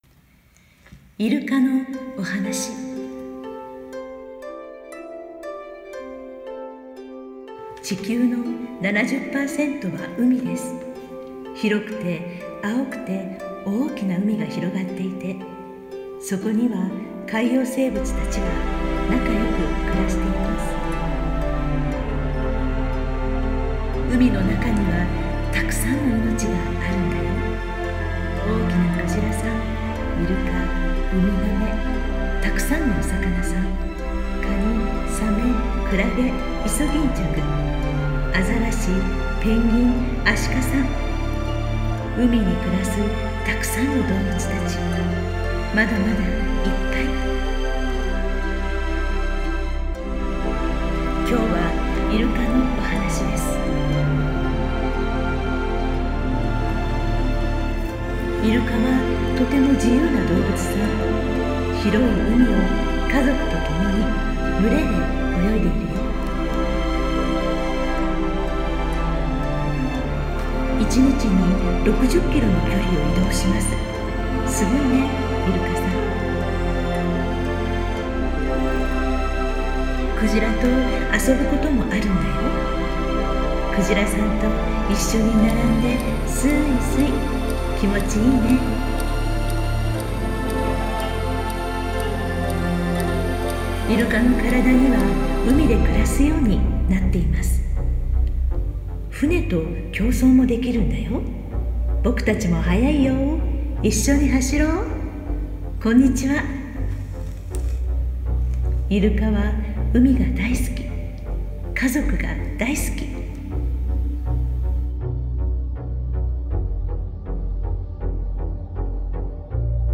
Soundtrack With voice